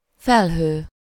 Ääntäminen
Ääntäminen France (Paris): IPA: [œ̃ ny.aʒ] Tuntematon aksentti: IPA: /nɥaʒ/ Haettu sana löytyi näillä lähdekielillä: ranska Käännös Ääninäyte Substantiivit 1. felhő Muut/tuntemattomat 2. tömeg 3. raj 4. sereg 5. felleg Suku: m .